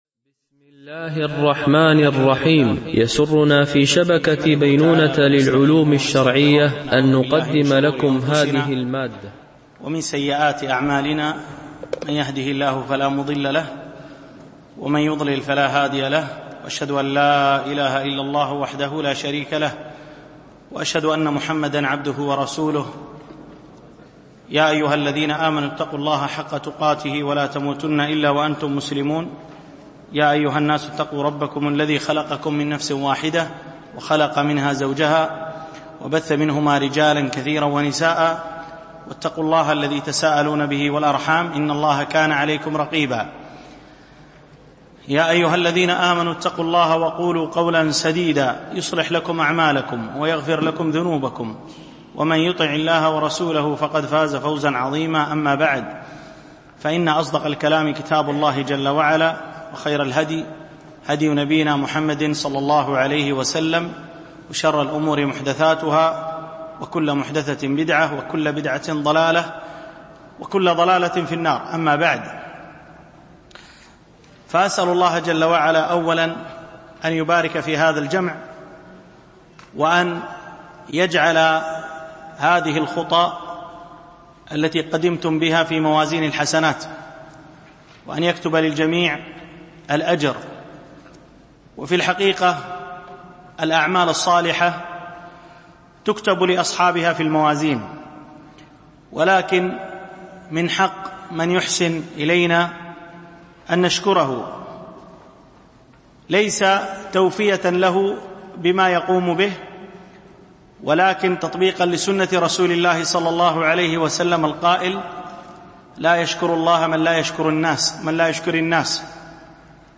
دورة علمية شرعية لمجموعة من المشايخ الفضلاء بمسجد أم المؤمنين عائشة - دبي (القوز 4)